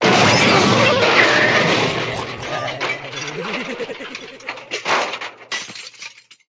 CS_EP2aMid_Crash.wav